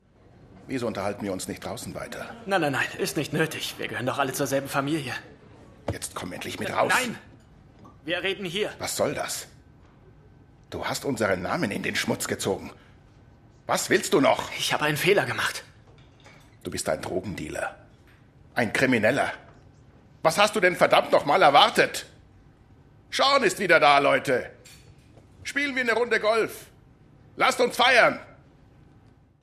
Sprachproben
Männlich
Mezzo-Sopran / Bariton / Mittel
Alt / Bass / Tief
Aber auch Sachtexte z.B. für Imagefilme, E-Learning, Werbung oder Dokumentationen, erwecke ich durch meine warme, sonore, vertrauenerweckende Stimme zum Leben.